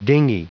Prononciation du mot dinghy en anglais (fichier audio)
Prononciation du mot : dinghy